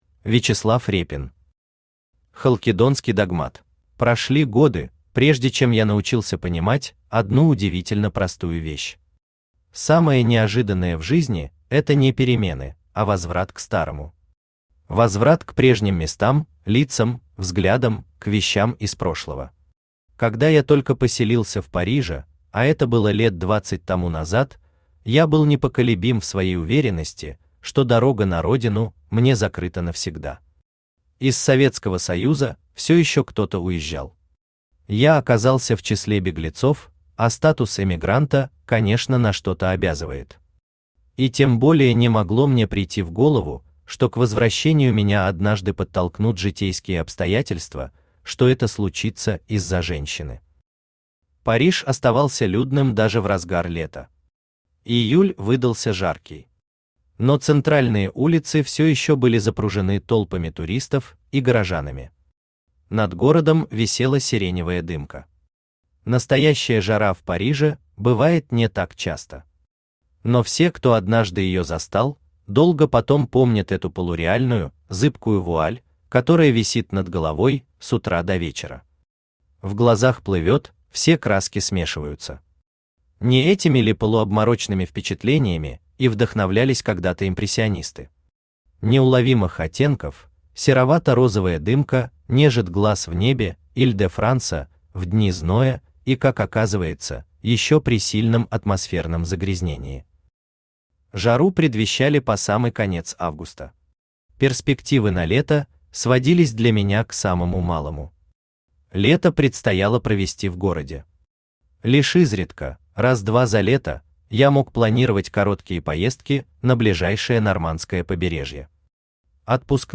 Аудиокнига Халкидонский догмат | Библиотека аудиокниг
Aудиокнига Халкидонский догмат Автор Вячеслав Борисович Репин Читает аудиокнигу Авточтец ЛитРес.